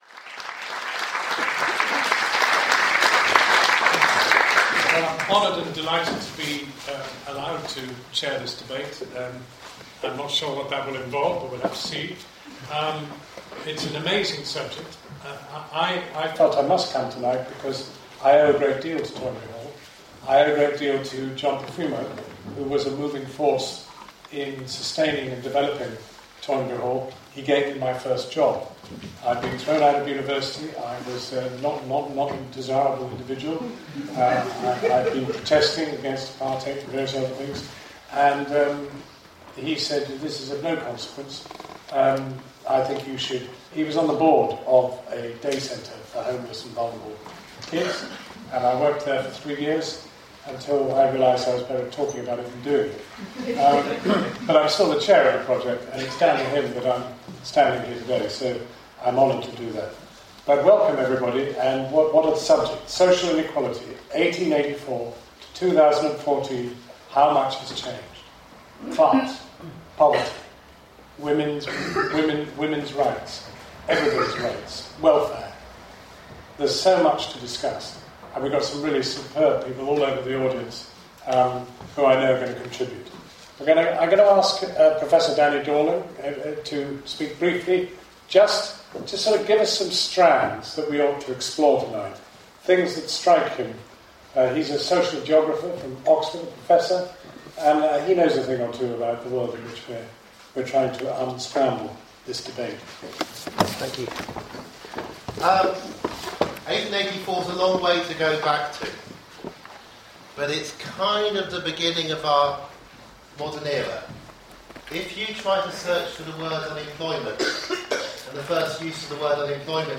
Danny Dorling speaking at Toynbee Hall, London, December 4th 2014